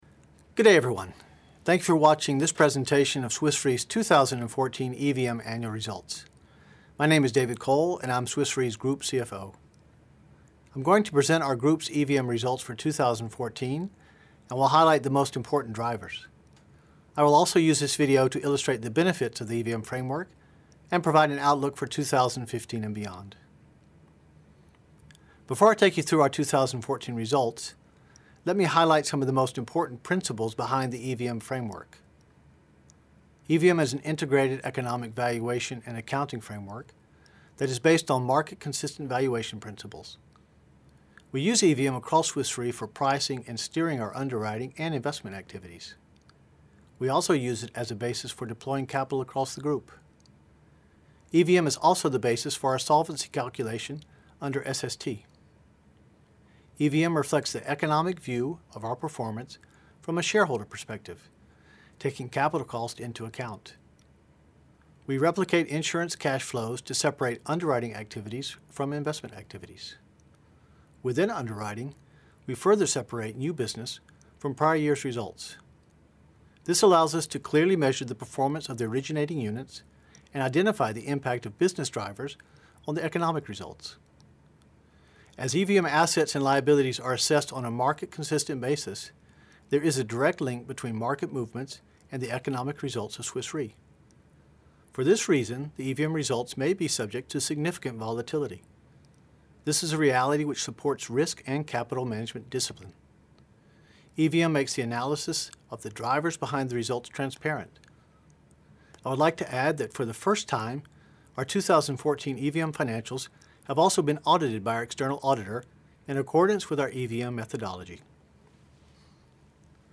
Annual EVM results 2014 video presentation recording